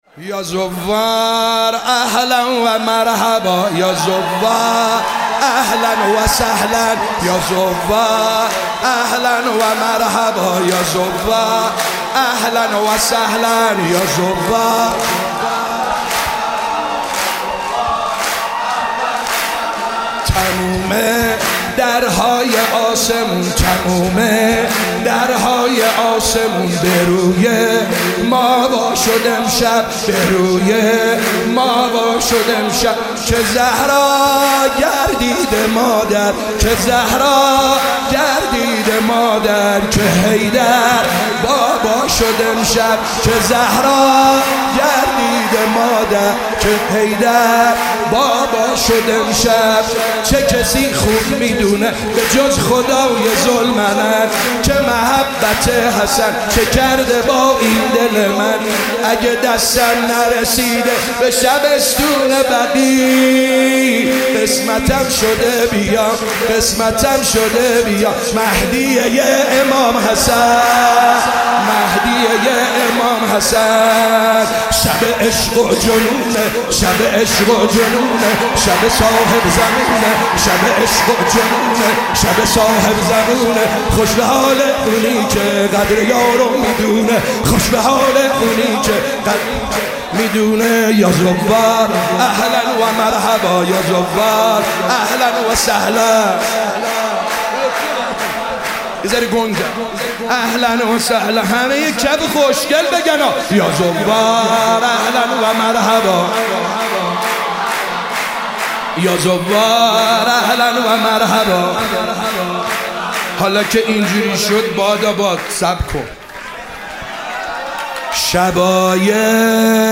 شب 15 رمضان 97- سرود - یا زوار اهلا و مرحبا